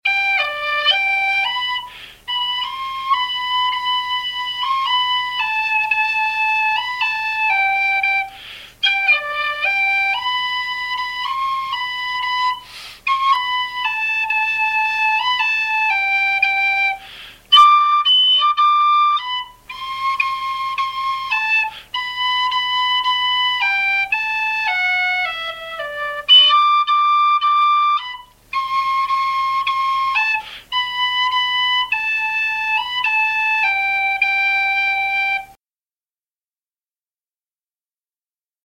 I'll Tell My Ma song G pdf 13KB txt